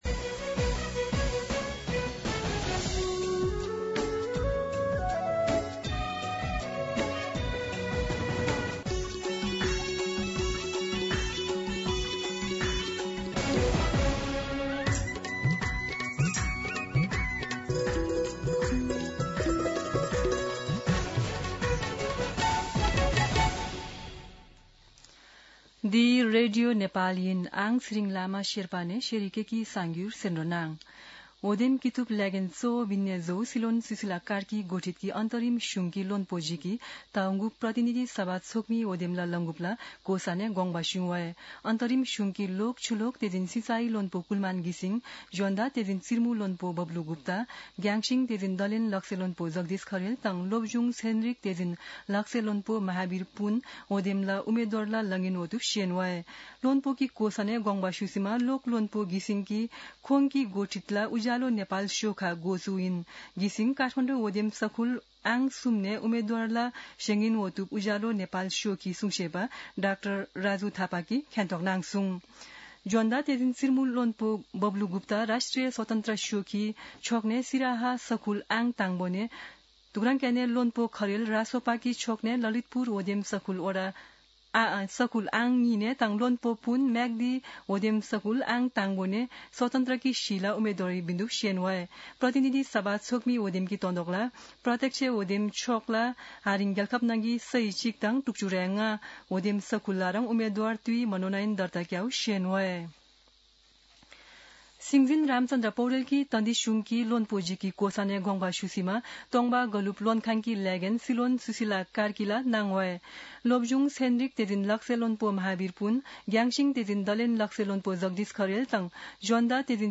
शेर्पा भाषाको समाचार : ६ माघ , २०८२
Sherpa-News-06.mp3